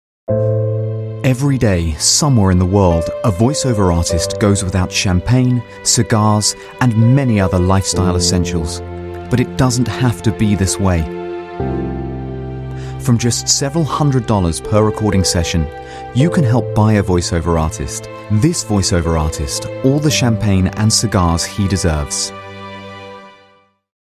Voice Samples: Reel Sample 03
male
EN UK